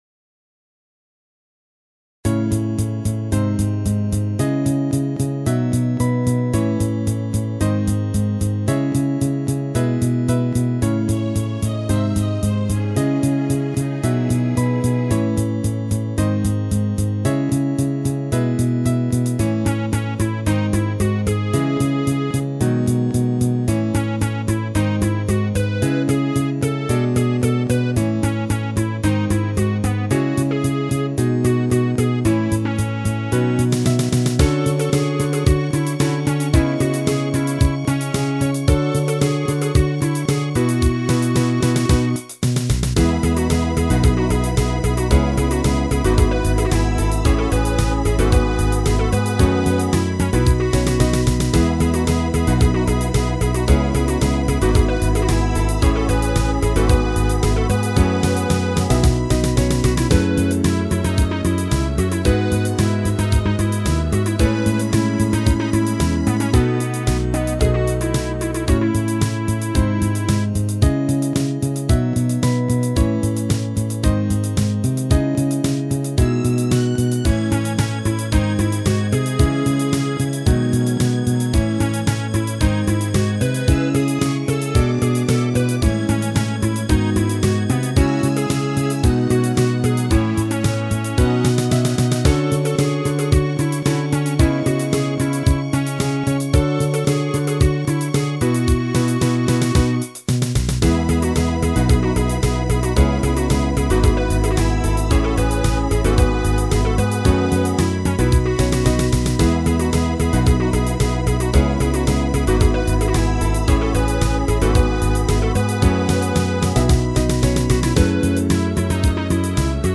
文字通り曲調の似たタイトル2曲のメドレー。